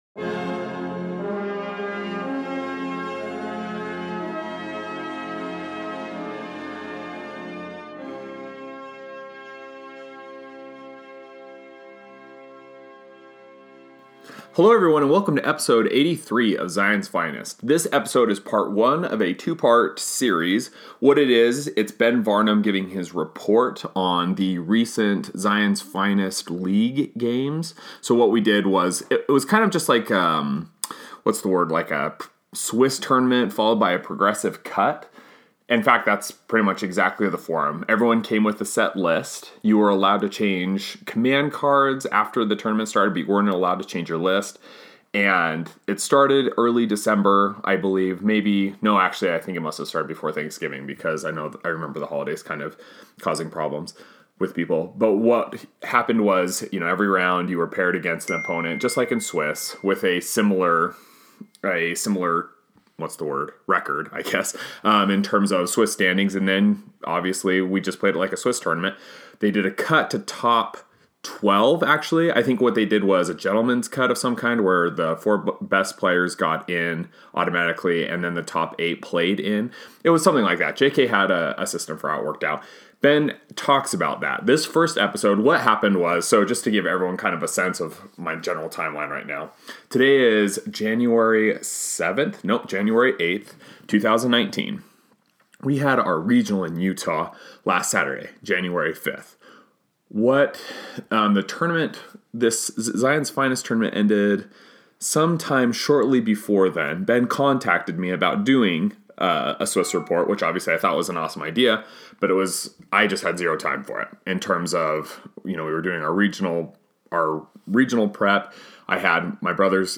← All episodes Ep 083 - Ascending the Swiss Mountain 2019-01-09 Mercenary IG-88 tournament-report interview